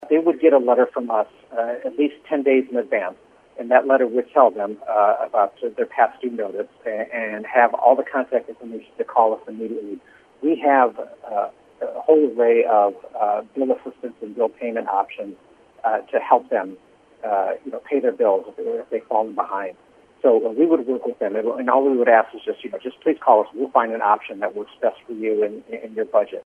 WCMY News